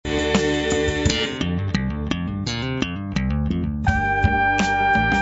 LE SLAP
- un petit riff sur le rap
riff_slap.mp3